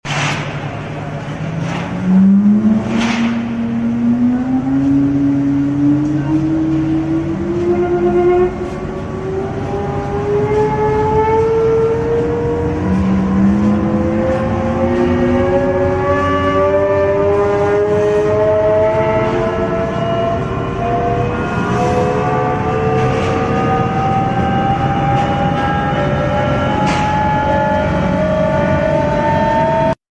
185KW Motor Turbine Unit Start sound effects free download
185KW Motor Turbine Unit Start Up